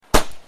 Fireworks Pang